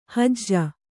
♪ hajja